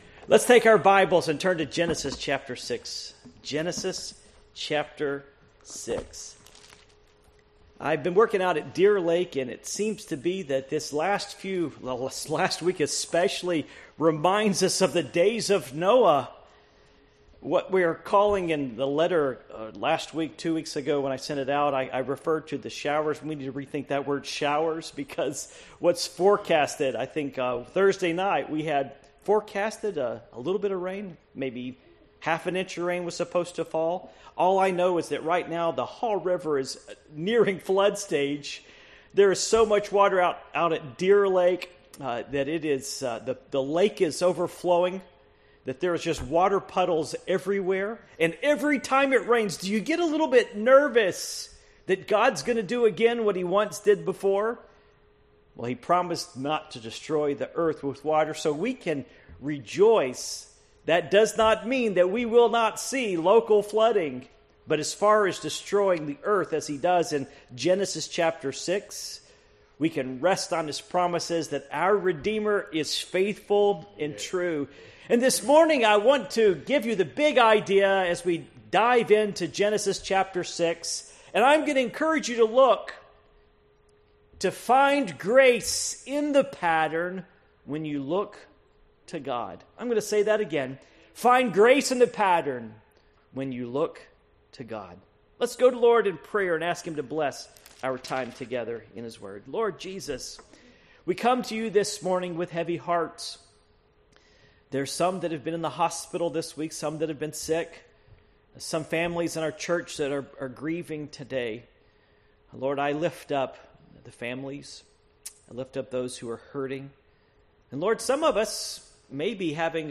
Passage: Genesis 6:1-8 Service Type: Morning Worship